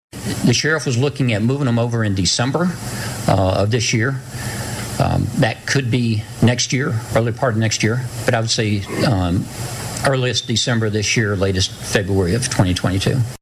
Branch County Administrator Bud Norman spoke this week to the Coldwater Noon Rotary Club providing an update on the 28-million dollar jail project for which ground was broken in October of 2019.